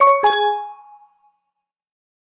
distress.ogg